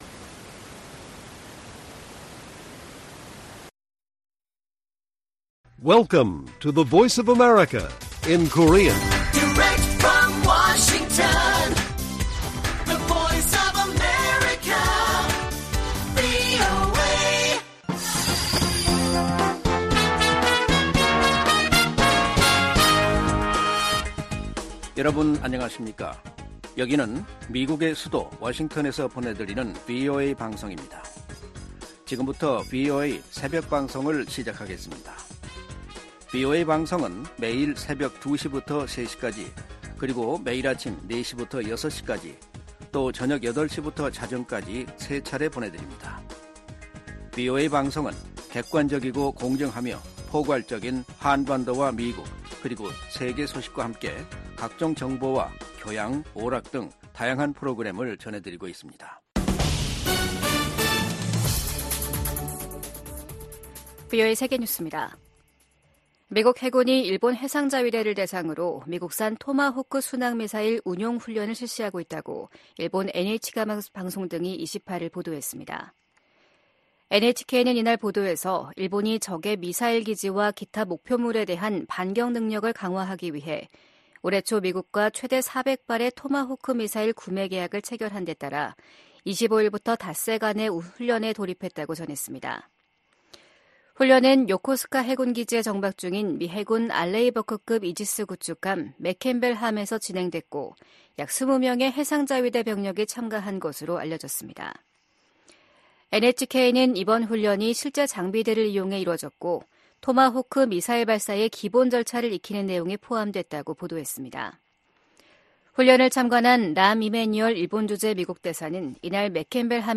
VOA 한국어 '출발 뉴스 쇼', 2024년 3월 29일 방송입니다. 미국과 한국이 공동으로 북한 국적자 6명과 외국업체 2곳에 대한 제재를 단행했습니다. 북한이 최근 원심분리기 시설을 확장하고 있는 것으로 보인다는 보도가 나온 가운데 미국 정부는 위험 감소 등 북한과 논의할 것이 많다는 입장을 밝혔습니다. 중국이 미일 동맹 격상 움직임에 관해, 국가 간 군사협력이 제3자를 표적으로 삼아선 안 된다고 밝혔습니다.